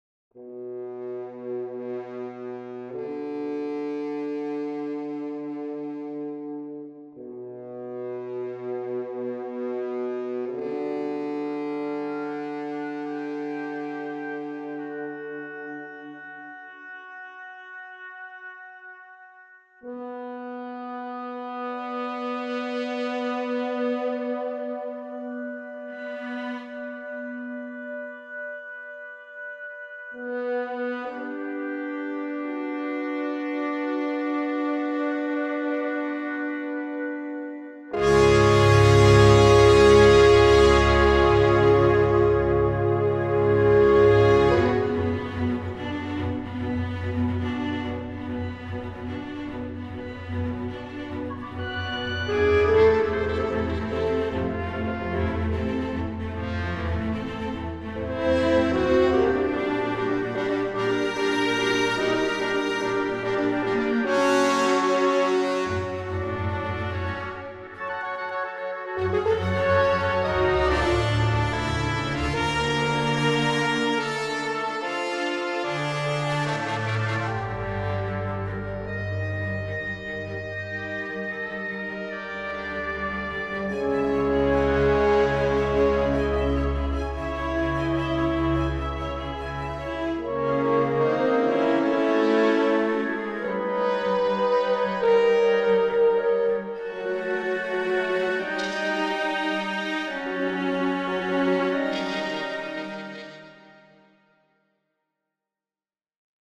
29. Cello (Cellos section/Arco)
30. Double Bass (Contrabasses section/Arco)